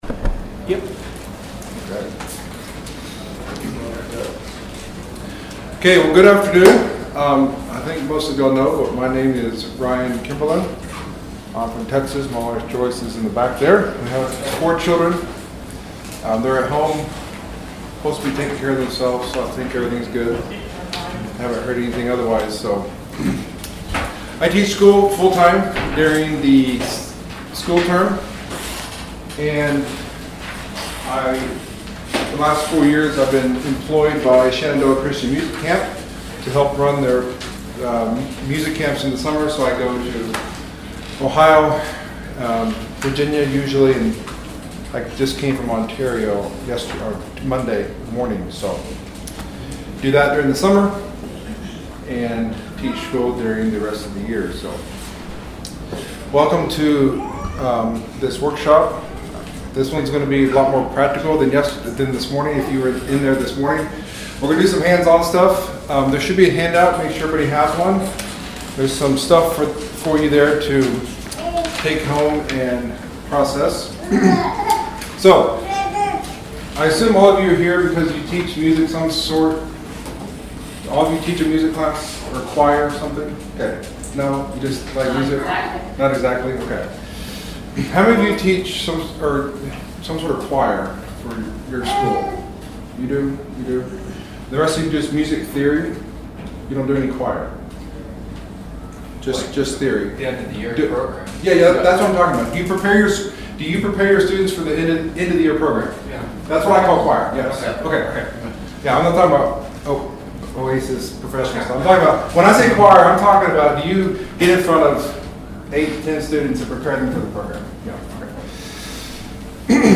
01:04 Download This practical, interactive workshop offers tips, tools, and demonstrations to help you teach upper-grade music with confidence and creativity. We’ll explore ways to build musical skills that students can use in church, school, and family settings.